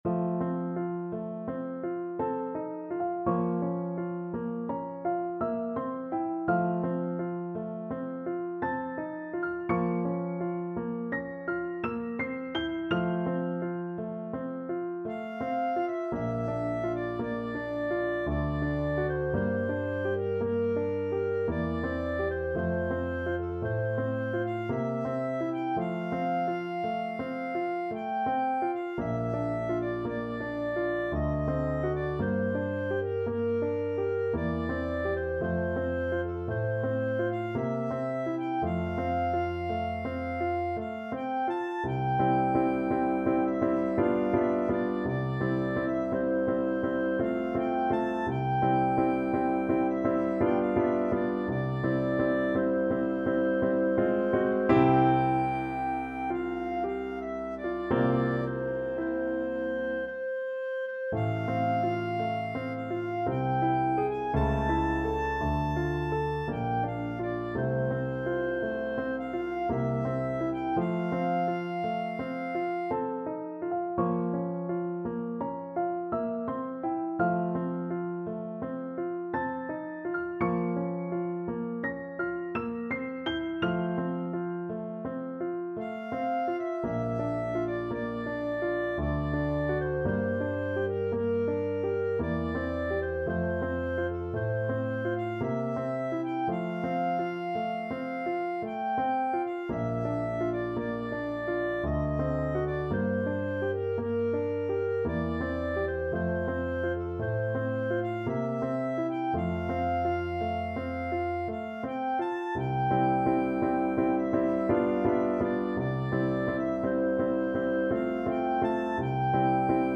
Classical Schubert, Franz Lob der Tranen, D.711 Clarinet version
3/4 (View more 3/4 Music)
F major (Sounding Pitch) G major (Clarinet in Bb) (View more F major Music for Clarinet )
~ = 56 Ziemlich langsam
Clarinet  (View more Easy Clarinet Music)
Classical (View more Classical Clarinet Music)